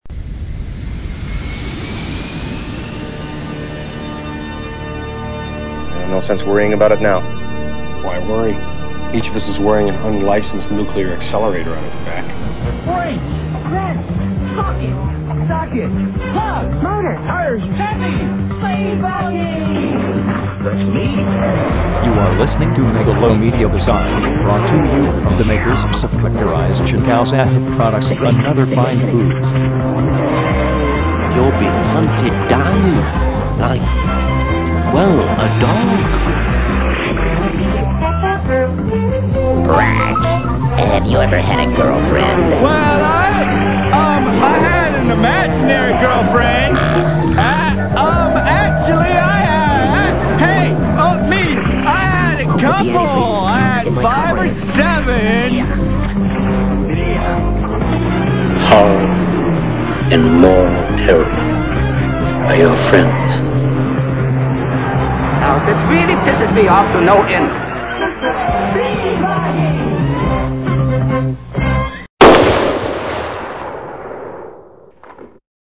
Audio Montage